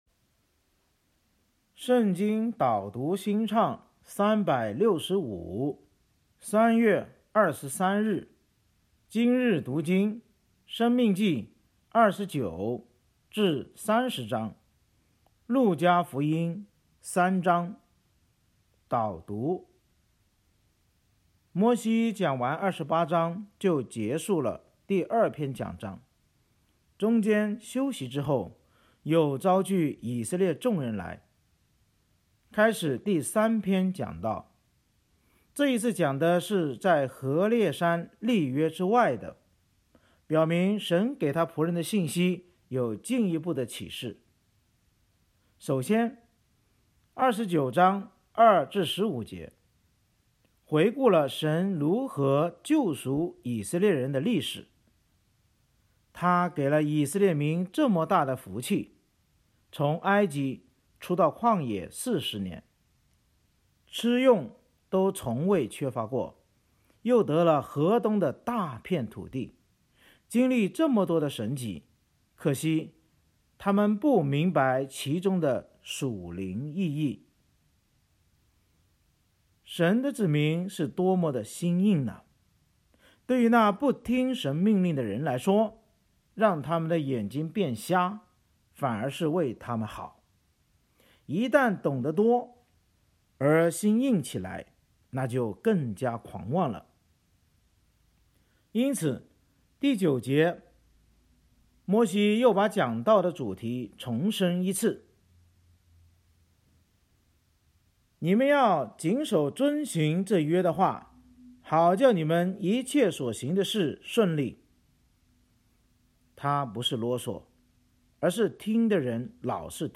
圣经导读&经文朗读 – 03月23日（音频+文字+新歌）